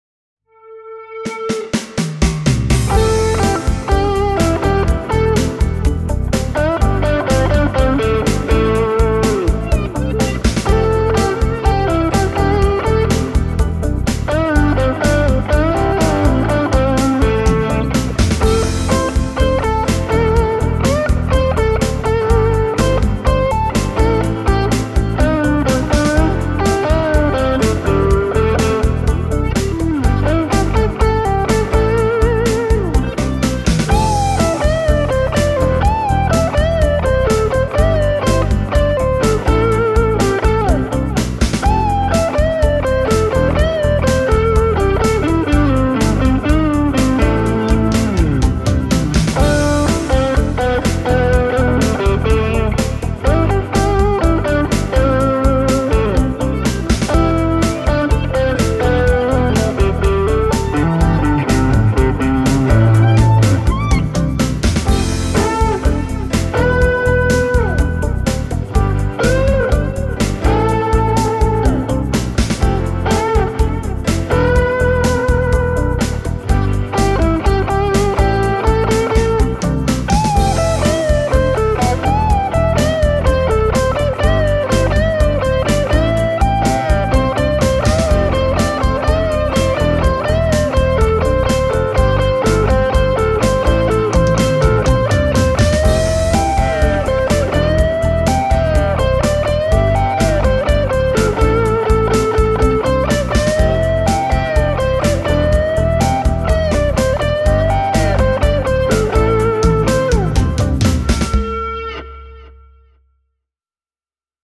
Mukana ovat silloin myös hyvin moniulotteiset rytmiset variaatiot (Complex Rhythmic) tai villit pitch shift -soundit (Compound Shifter), sekä lisää lo-fi-soundeja (Oil Can, Lo-Fi Retro, Warped Record, Binson).
Tässä lyhyt klippi muutamasta viivepatchista: